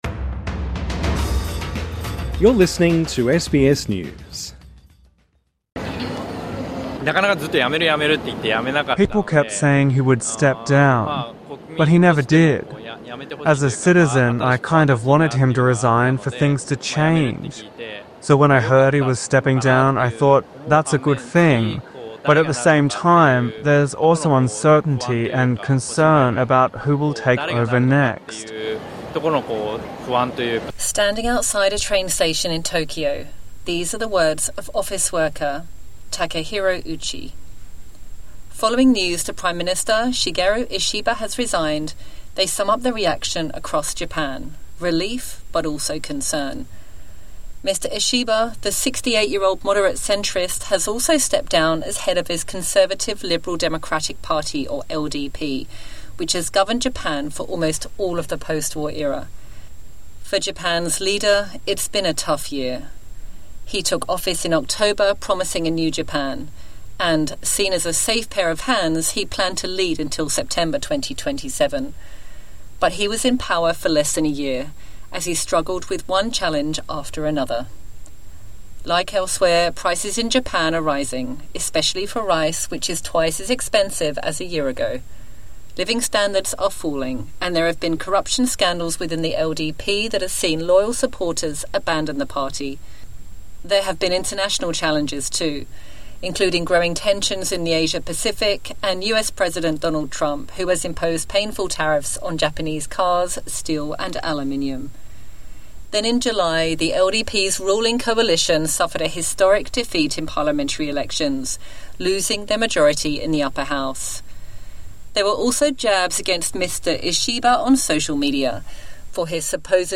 Listen to Australian and world news, and follow trending topics with SBS News Podcasts.